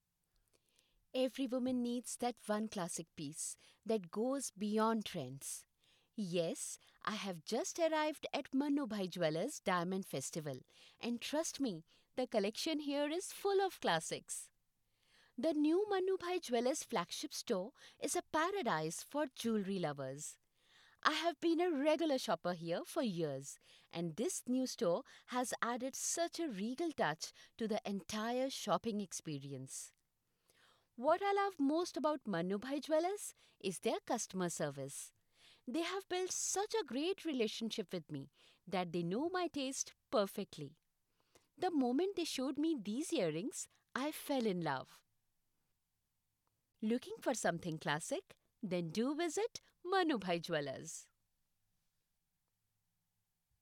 Female
My voice is pleasant , soft and compassionate.
Impersonations